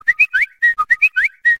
WHISTE ZAPDUPLO
whiste-zapduplo.mp3